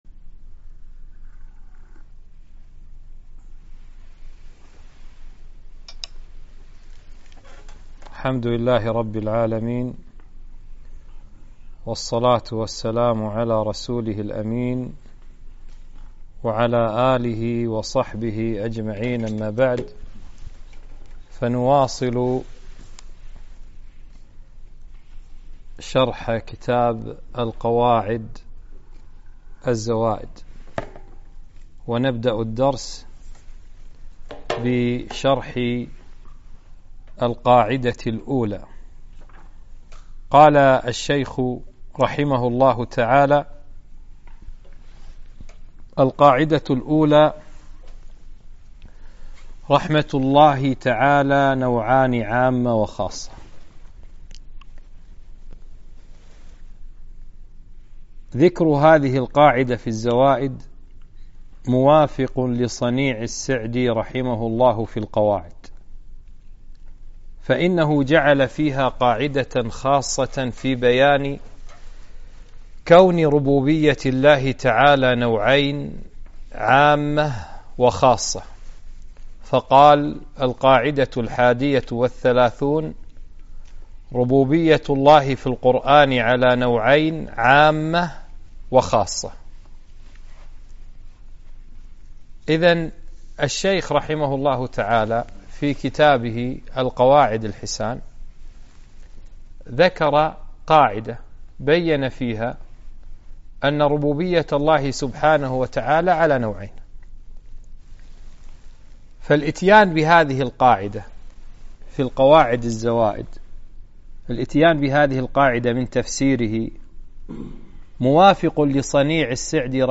2- القراءة والتعليق على كتاب القواعد الزوائد - المجلس الثاني